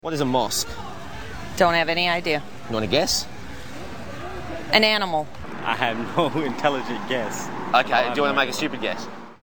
Category: Television   Right: Personal
Tags: Stupid Americans interview funny stupid americans